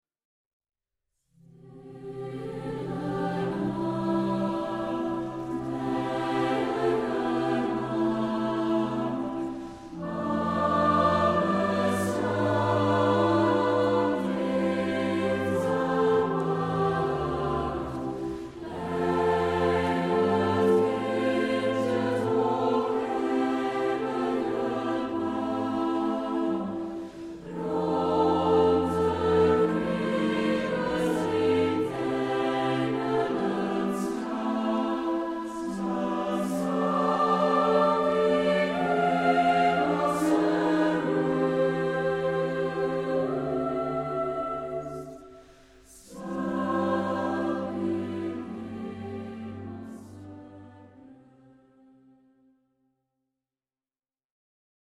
Concertband & Choir